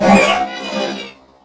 CosmicRageSounds / ogg / general / combat / enemy / droid / hurt1.ogg